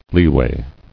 [lee·way]